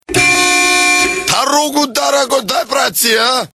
Главная » Рингтоны » Рингтоны приколы